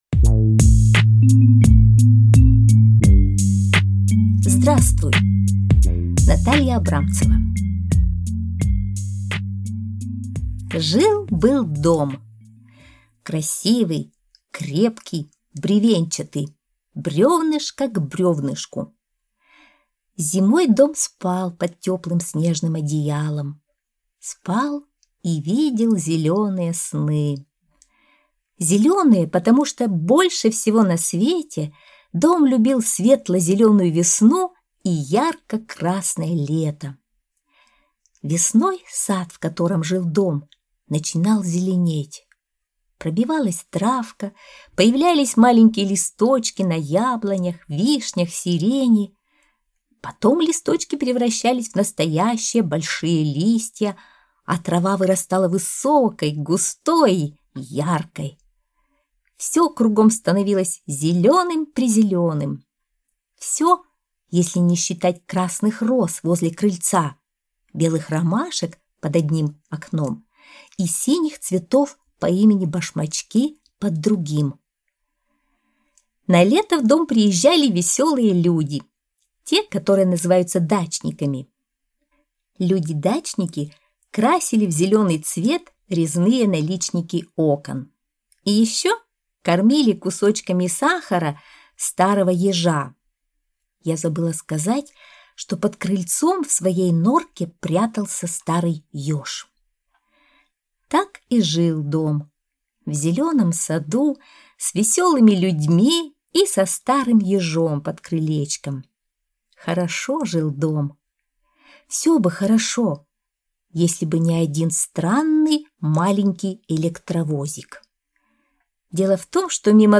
Аудиосказка «Здравствуй!»